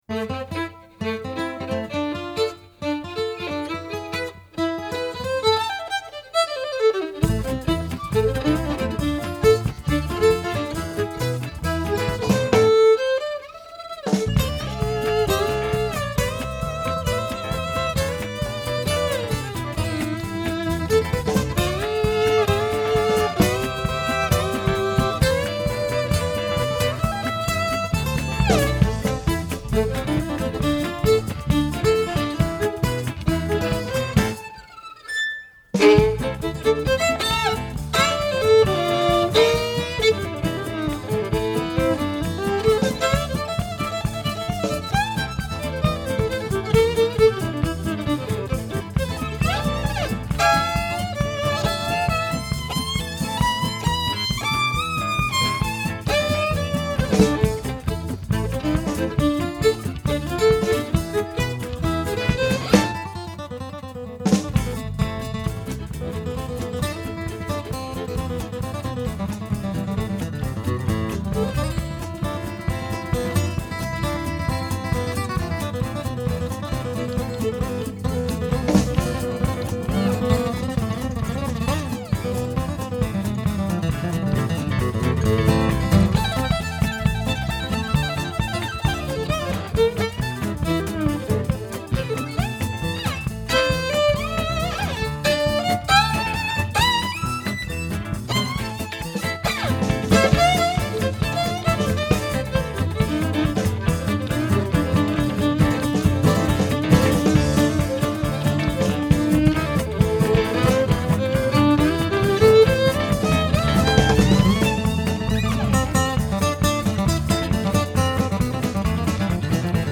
dance band
fiddle and lap steel
guitar and vocals
bass
drums